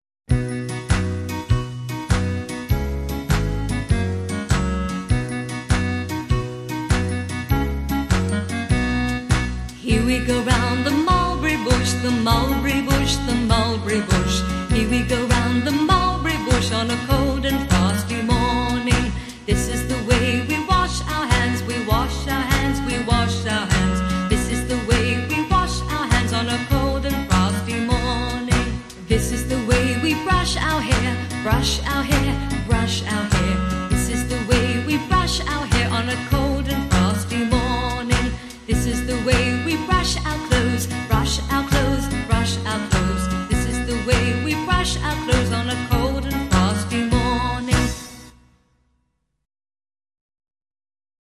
Here We Go Round The Mulberry Bush -английская песня-шутка. Шуточная песенка для детей, изучающих английский язык.